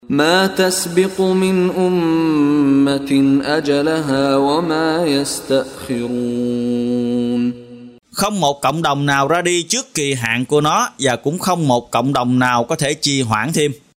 Đọc ý nghĩa nội dung chương Al-Hijr bằng tiếng Việt có đính kèm giọng xướng đọc Qur’an